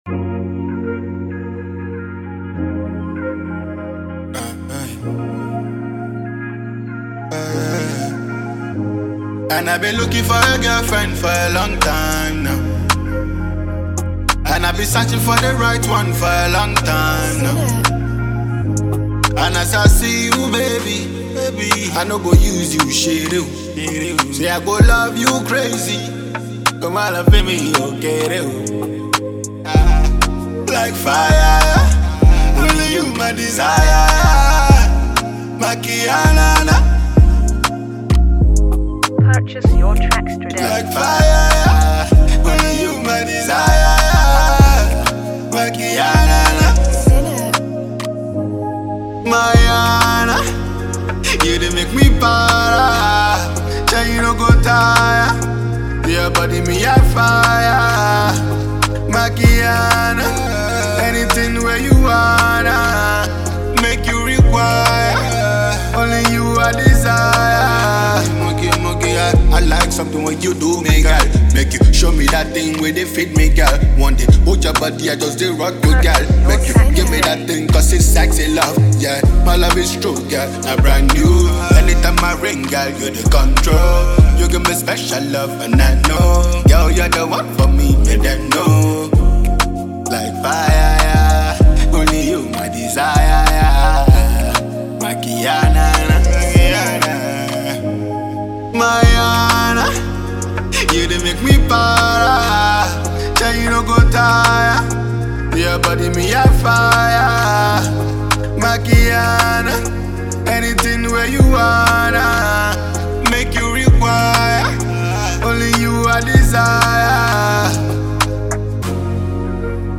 fresh blend of modern hip-hop and soulful melodies